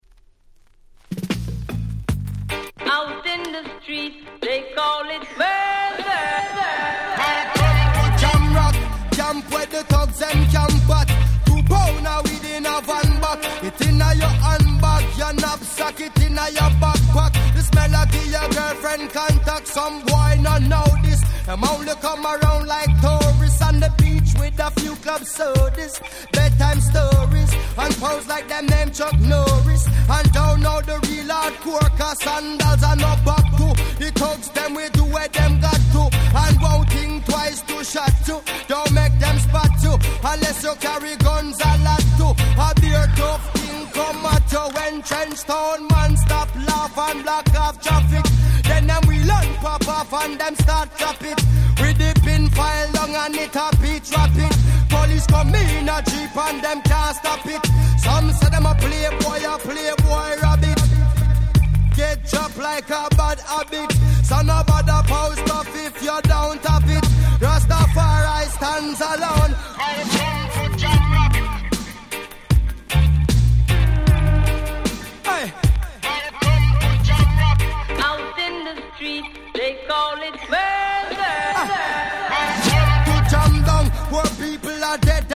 05' Super Hit Reggae !!